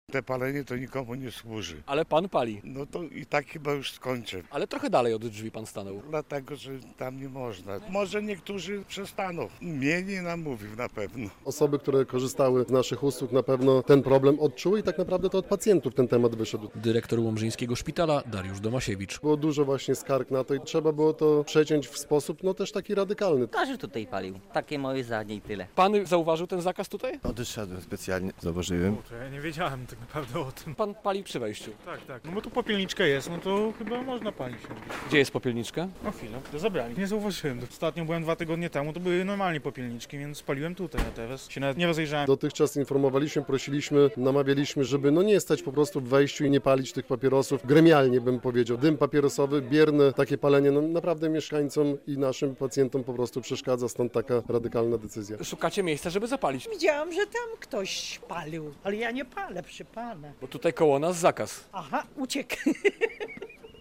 Koniec palenia przed szpitalem w Łomży - relacja